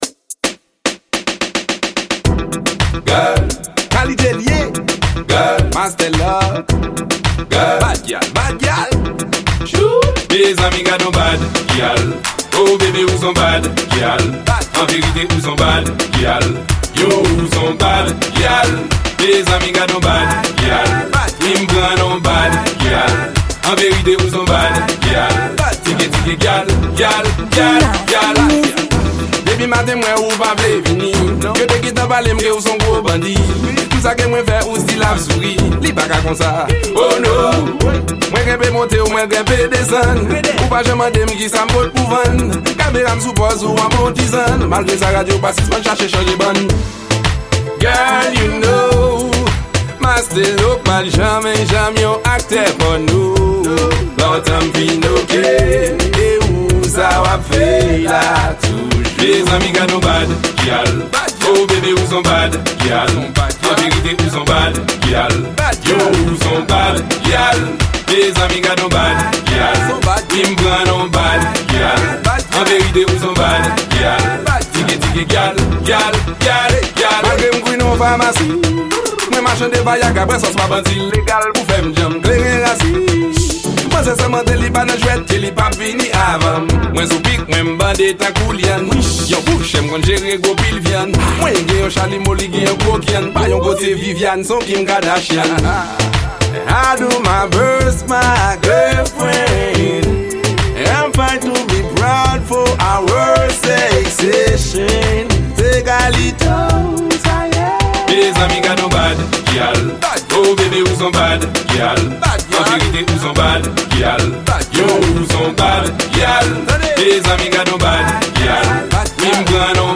Genre: Dance Hall.